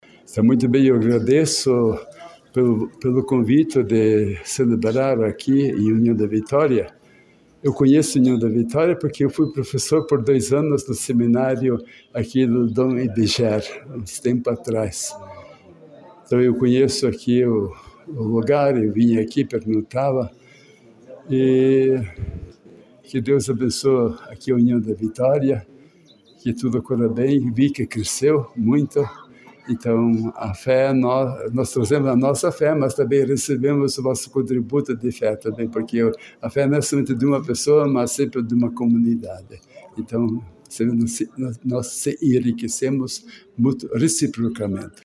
O bispo Don Dionizio falou a reportagem sobre a alegria de estar retornando a União da vitória pois foi professor do seminário por dois anos//